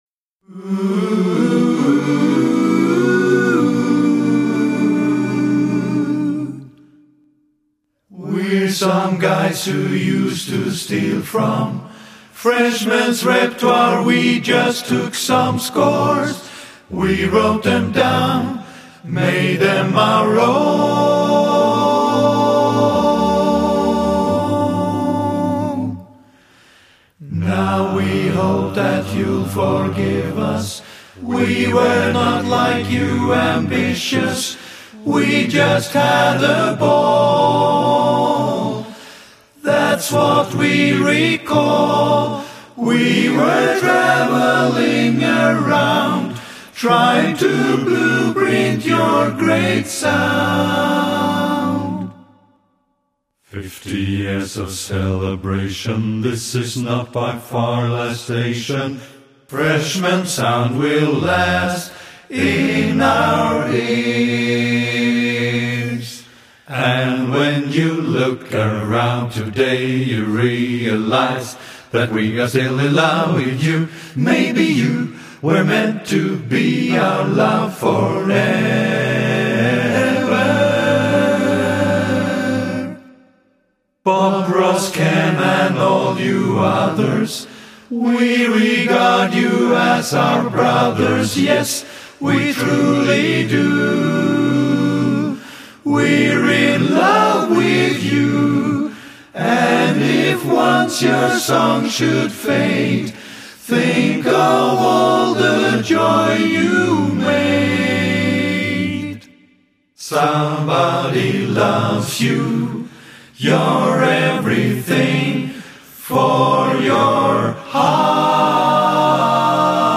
his quartet performed